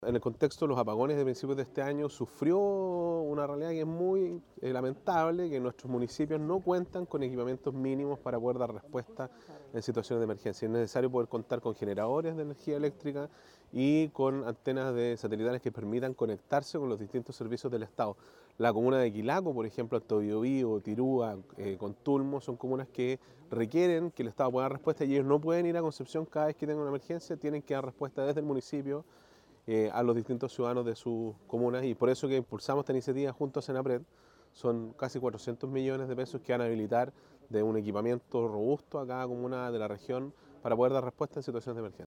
Es necesario que puedan contar con energía eléctrica y antenas satelitales para conectarse con los servicios del Estado”, señaló el gobernador Giacaman.